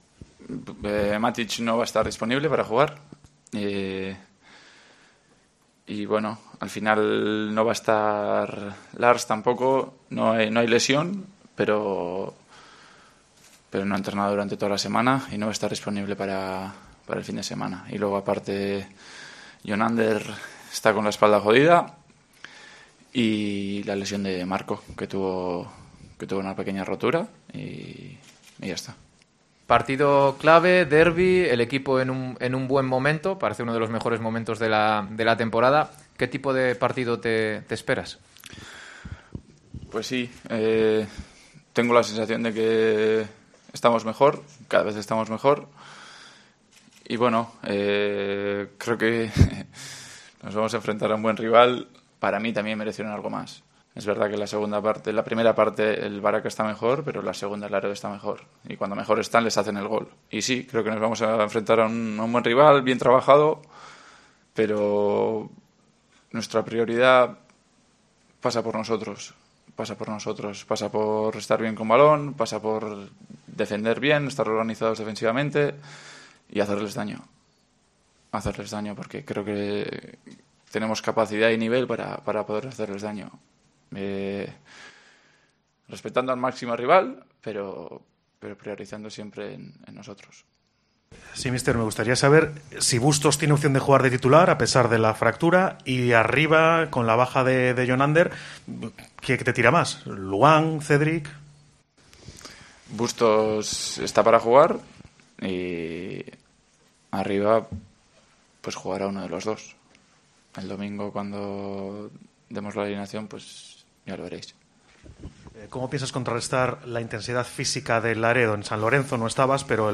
Escucha la previa del Racing-Laredo con las ruedas de prensa